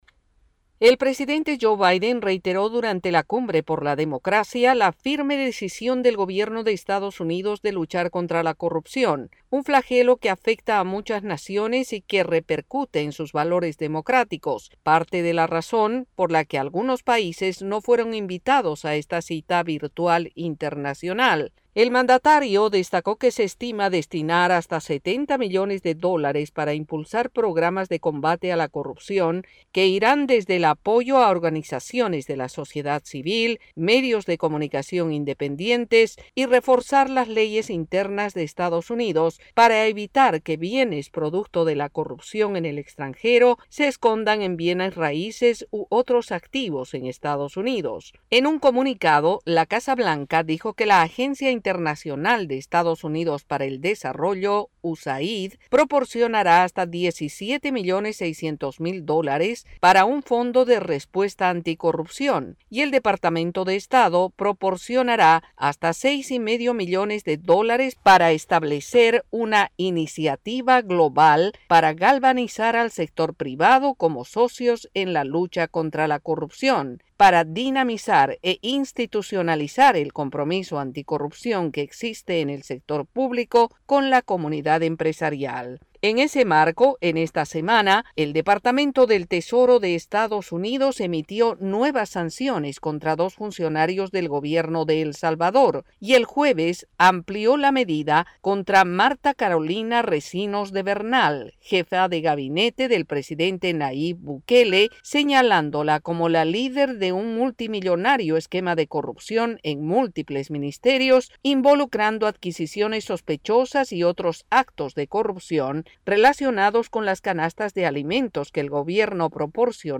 desde la Voz de América en Washington, DC.